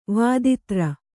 ♪ vāditra